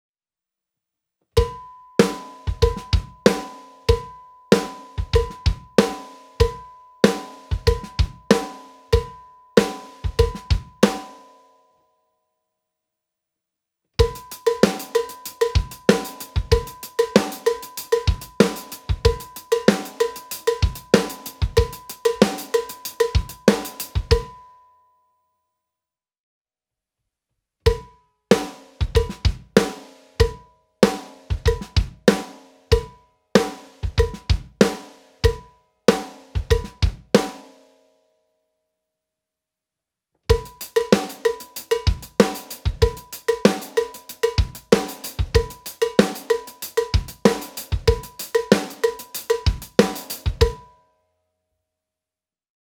MEINL Percussion Steel Craft Line Timbales Cowbell - 8 1/2" (SCL850-BK)
With their controlled sound and variety of pitches, the MEINL Percussion Steel Craft Cowbells are the go-to for any rhythm player.